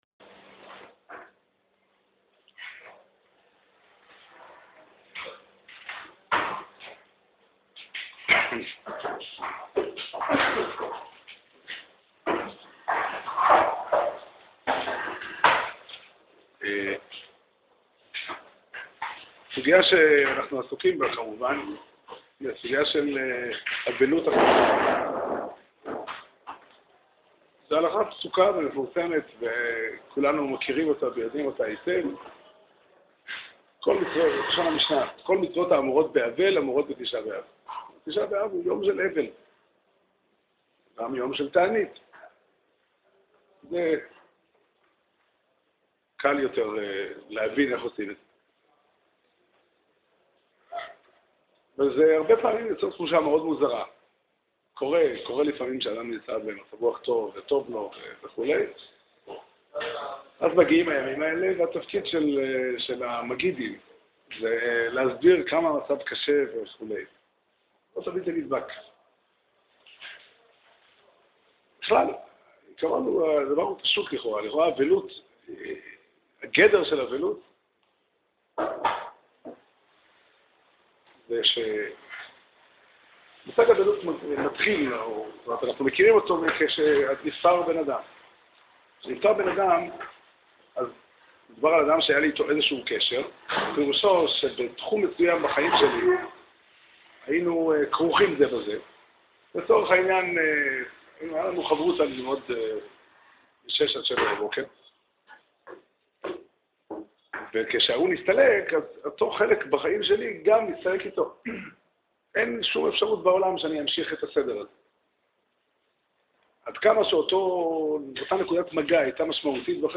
שיעור שנמסר בבית המדרש פתחי עולם בתאריך ז' אב תשע"ד